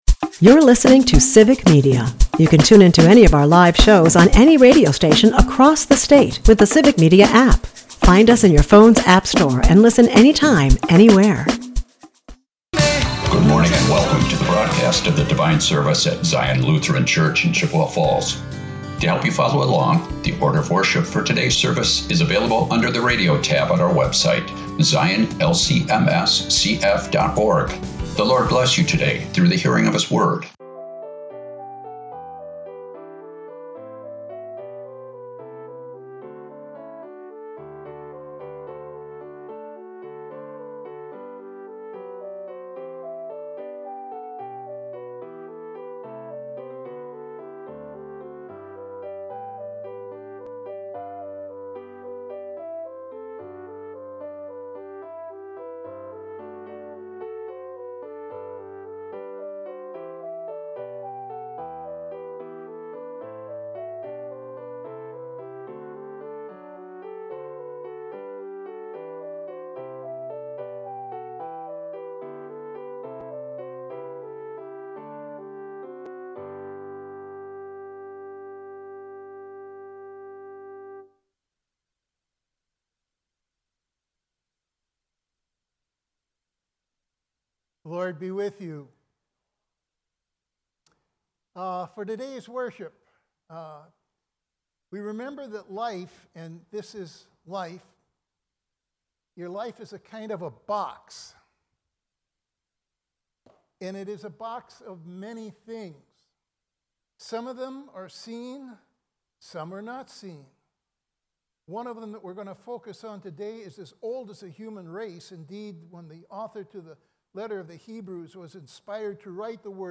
Sunday Service - Civic Media